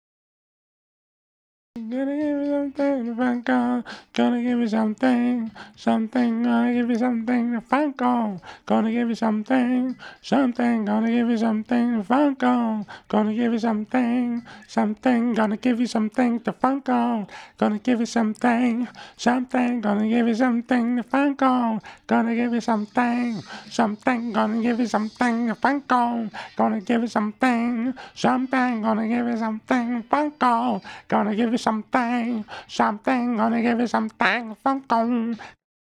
DF_107_E_FUNK_VOX_06 .wav